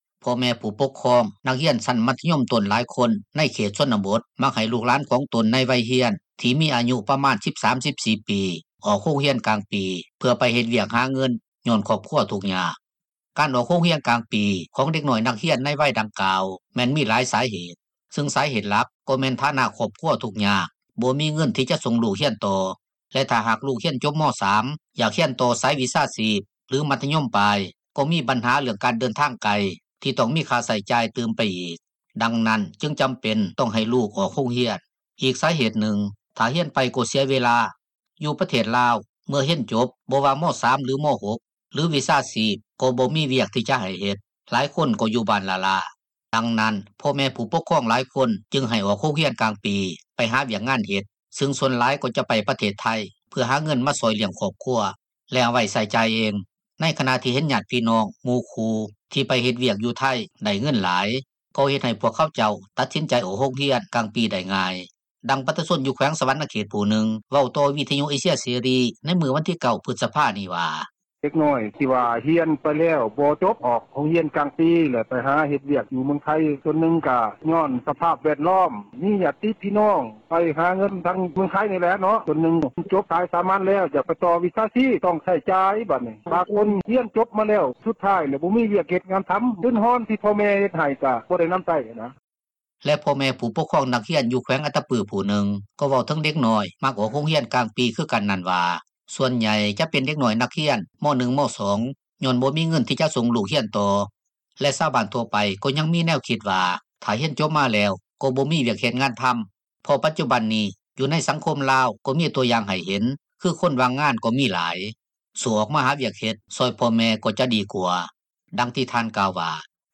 ດັ່ງປະຊາຊົນ ຢູ່ແຂວງສວັນນະເຂດ ຜູ້ນຶ່ງເວົ້າຕໍ່ວິທຍຸ ເອເຊັຽເສຣີ ໃນມື້ວັນທີ 9 ພຶສພານີ້ວ່າ: